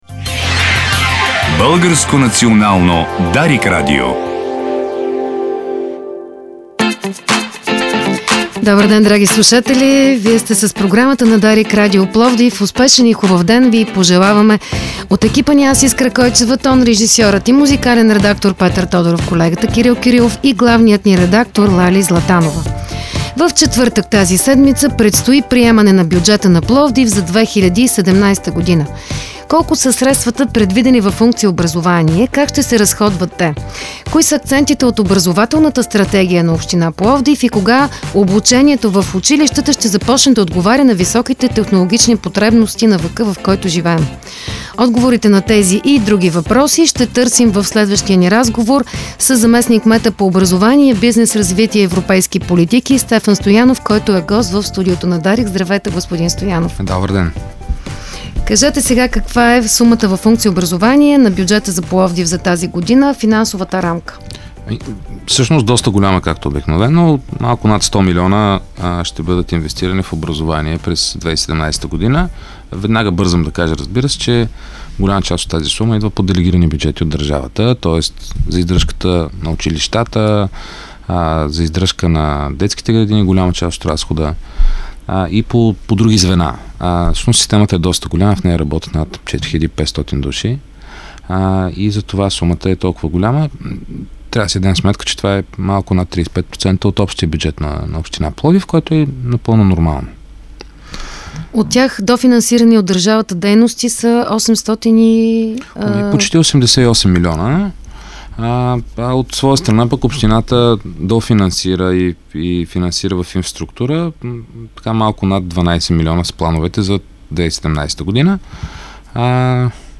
За първи път всички иновации, които Пловдив въвежда в образованието, се събират в общинска тригодишна стратегия. Това съобщи в студиото на Дарик зам.-кметът Стефан Стоянов.